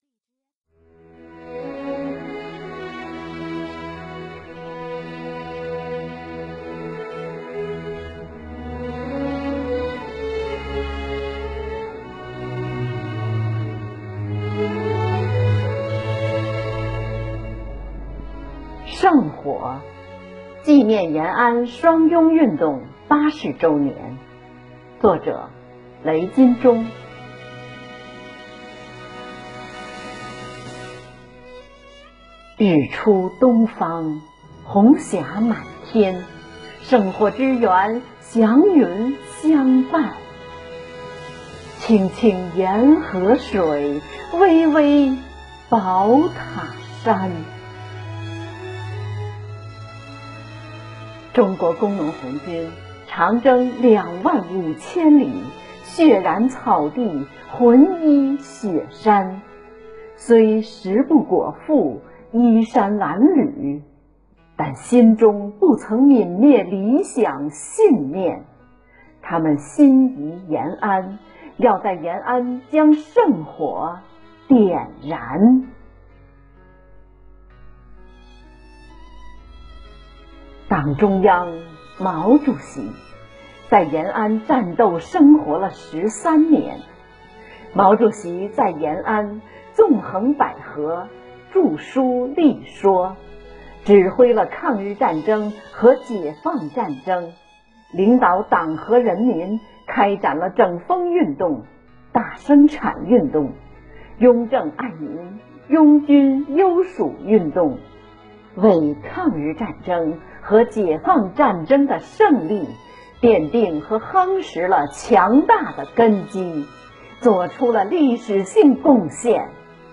二位退役军人（当代诗人）以诗朗诵的形式代表全体退役军人向“八一”建军节表示祝贺。